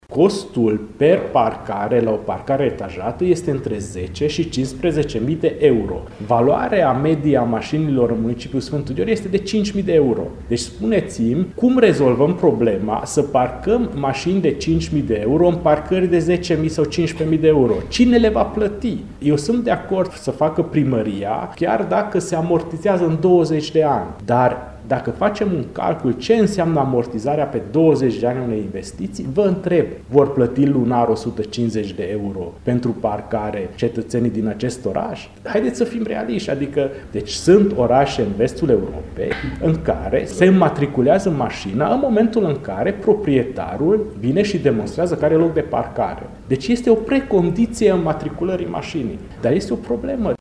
Primarul municipiului Sfântu Gheorghe, Antal Arpad a declarat că municipalitatea nu poate ţine pasul, în ce priveşte parcările, cu ritmul de creştere a numărului de maşini.